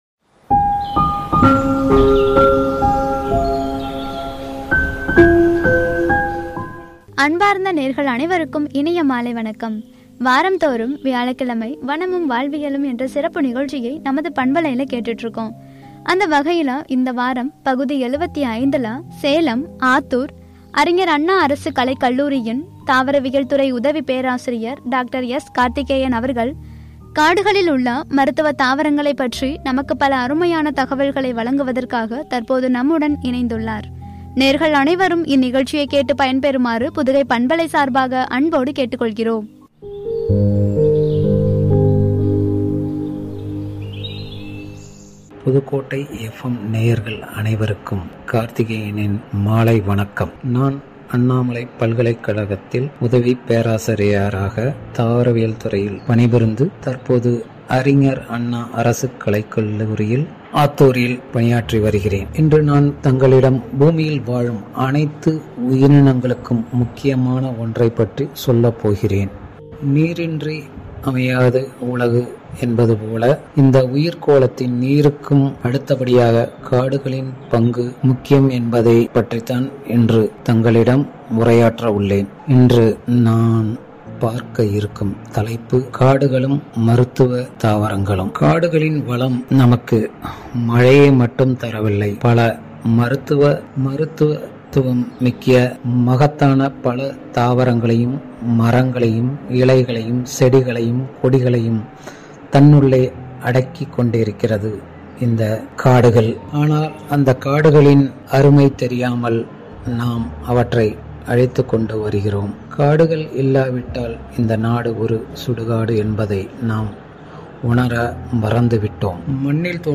வனமும் வாழ்வியலும் (பகுதி 75“காடுகளும், மருத்துவ தாவரங்களும்” என்ற தலைப்பில் வழங்கிய உரை